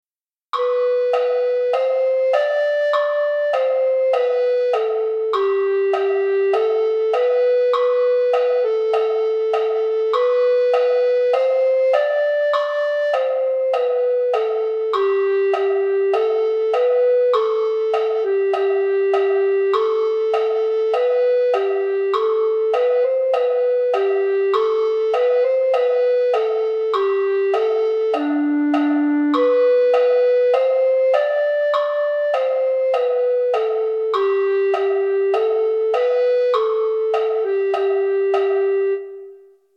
Arreglo para flauta dulce en pdf y mp3.
SOL M……RE M